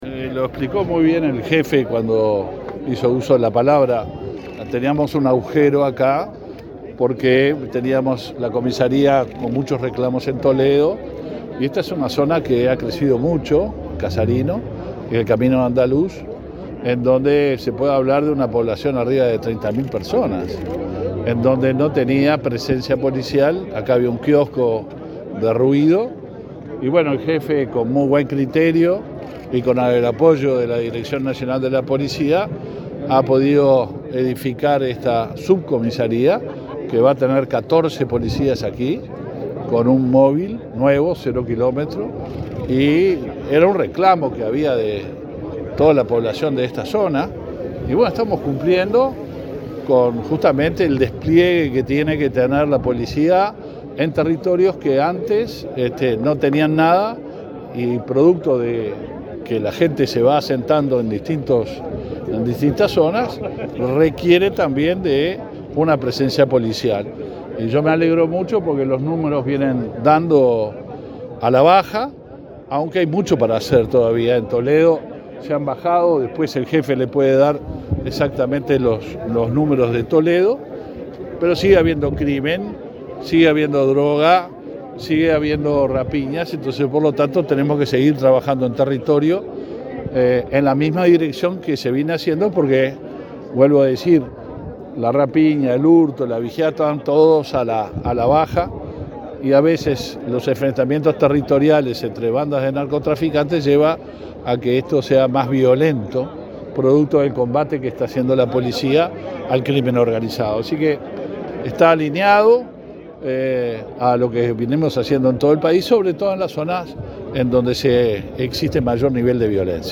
Declaraciones del ministro del Interior, Luis Alberto Heber
El ministro del Interior, Luis Alberto Heber, participó en la inauguración de una subcomisaría en la localidad de Casarino, departamento de Canelones.
Luego dialogó con la prensa.